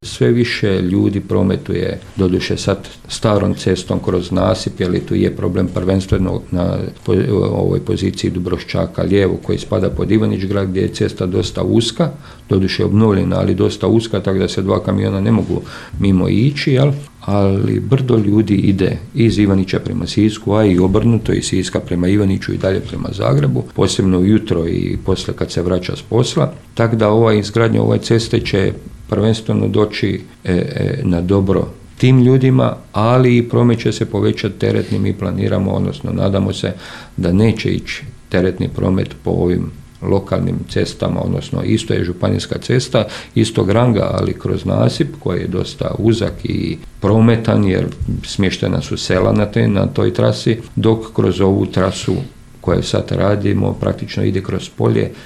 Više o tome, načelnik Stjepan Ivoš